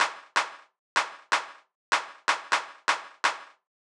IBI Beat - Claps.wav